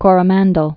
(kôrə-măndl)